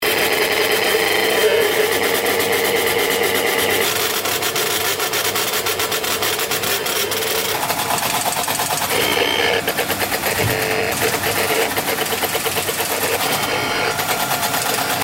… welches die Maschine ganz schön zum Rattern bringt …